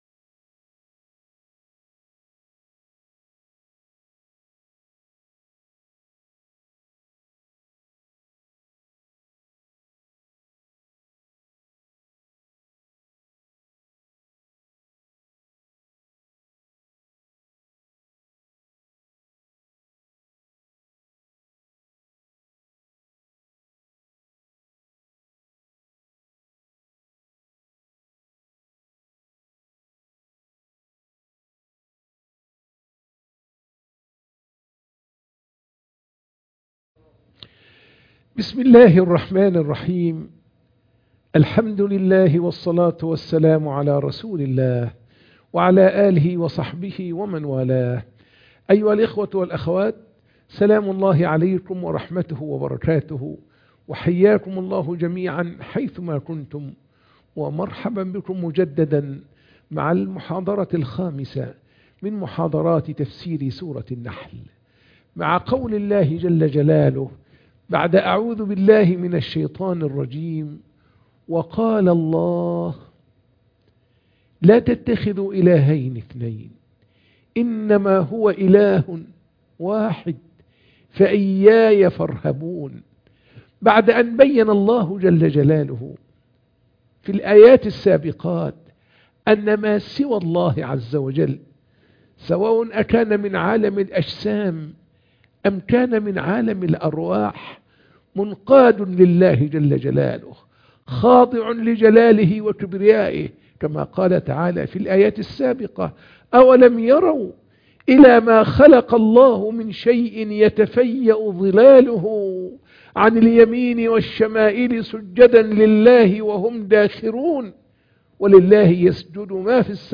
تفسير سورة النحل 51 - المحاضرة 5